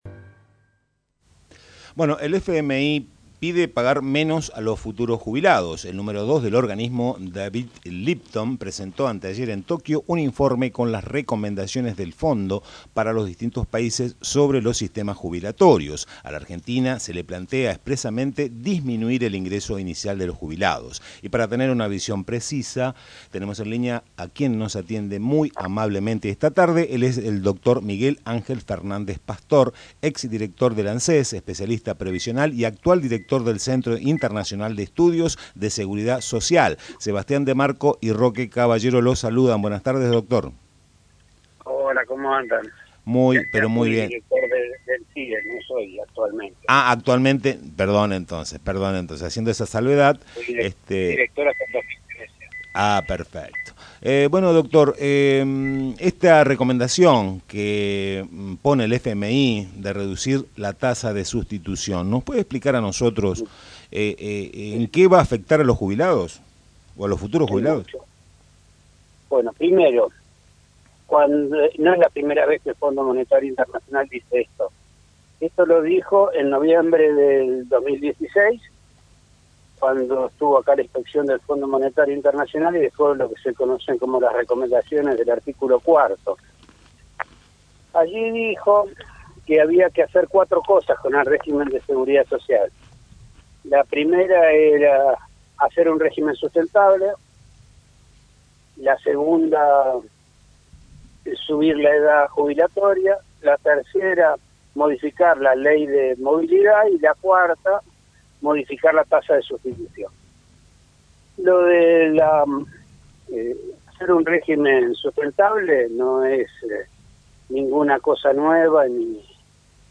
Tren de verano dialogó con el Drr. Miguel Ángel Fernández Pastor ex director del ANSES quien detalló la intención del organismo internacional, el cual desea instalar un régimen similar al que tiene Chile y México con tres tipos de jubilados.